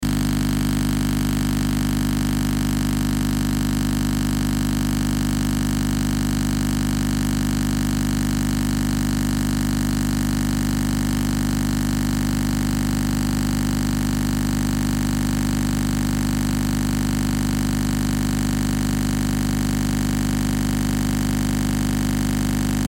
Звуки аудио-колонки
Звук неисправной компьютерной колонки